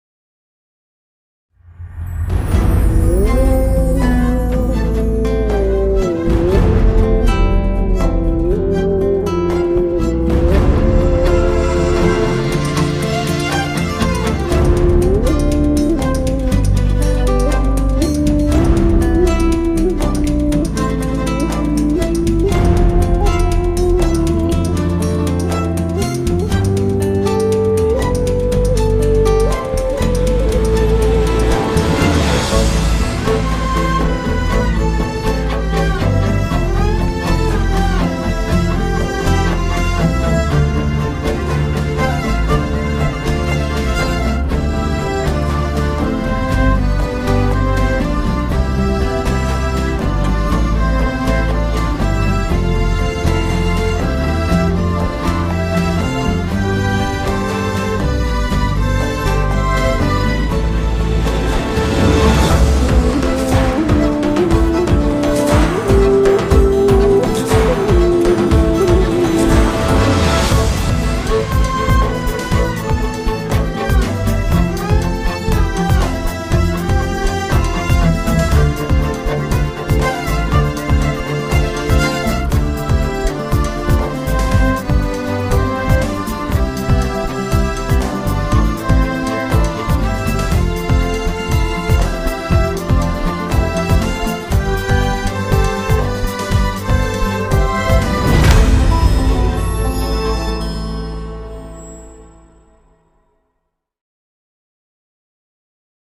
duygusal hüzünlü rahatlatıcı fon müziği.